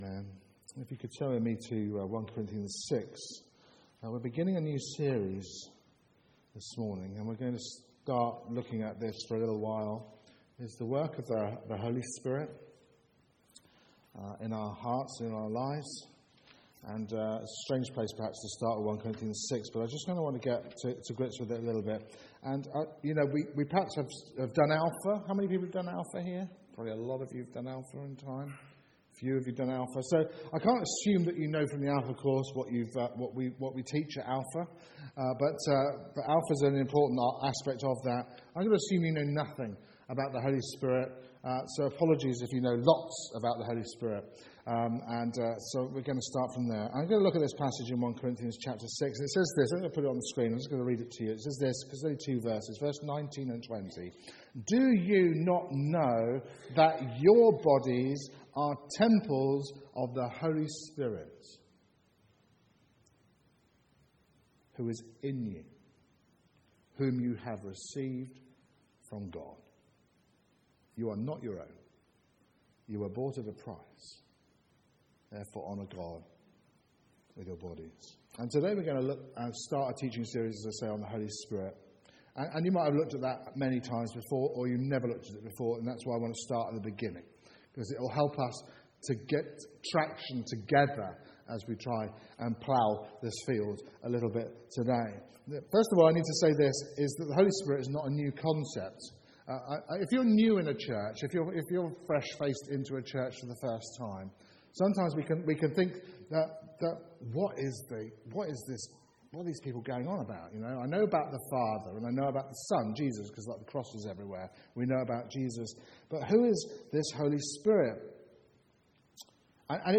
Work of the Holy Spirit Sermon